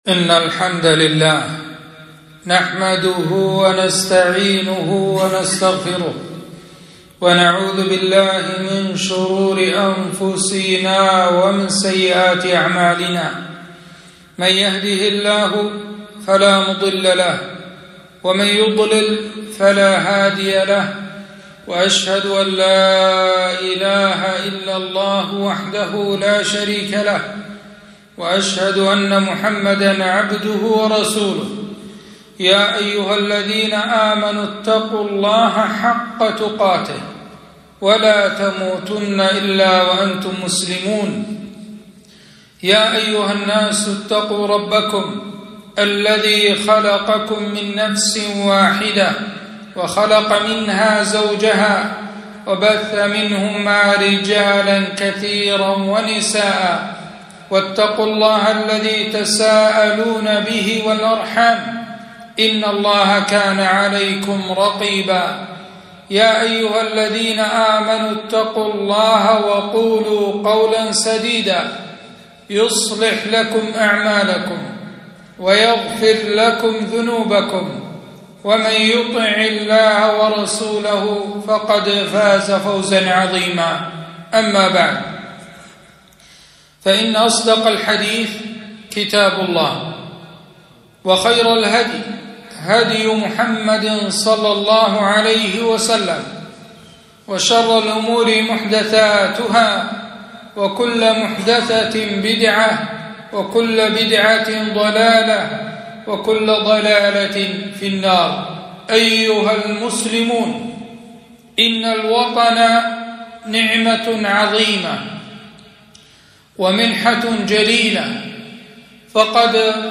خطبة - حب الوطن